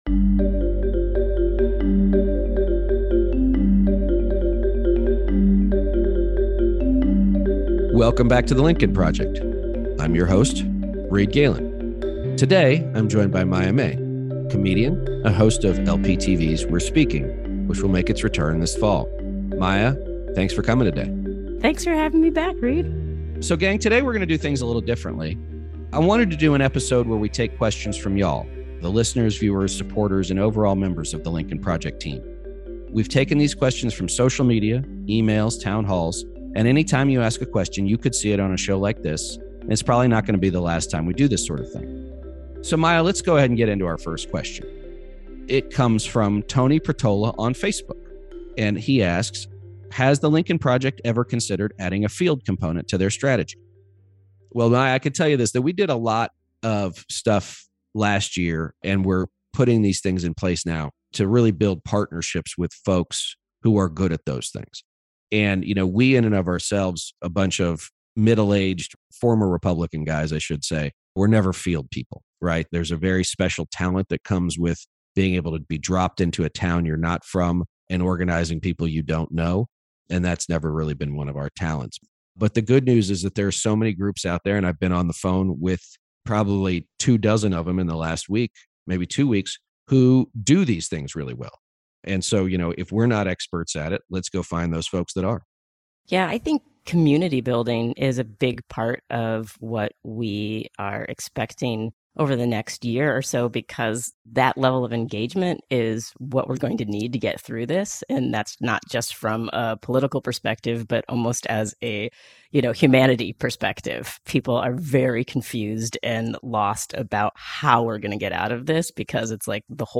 For this podcast, we’re shaking up the format and answering questions asked by YOU, the members of the Lincoln Project community. Listeners ask about the impact evictions may have on voters who need to get a new address/voter ID, how the 2020 census data will be used for redistricting, can the Supreme Court be the answer to the latest wave of anti-voting rights legislation, and so much more!